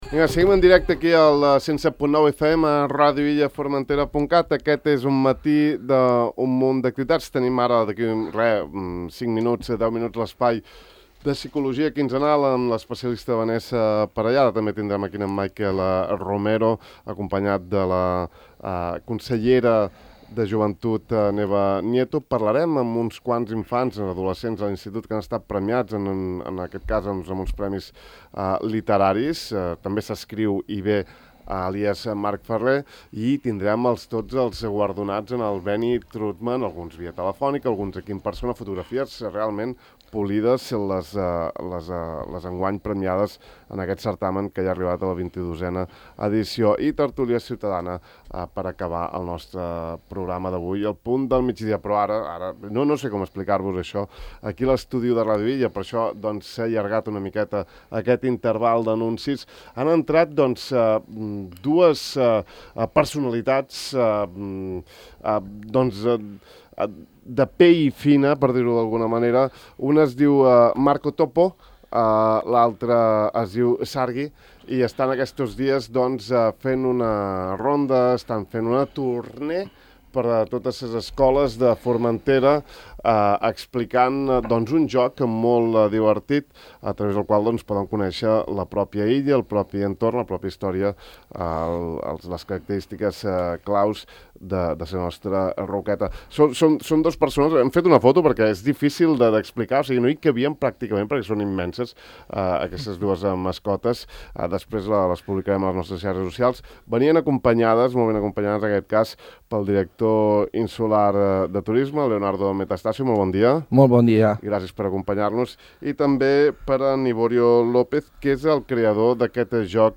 El director insular de Turisme
l’han presentat avui a Ràdio Illa.